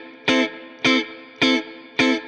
DD_StratChop_105-Amin.wav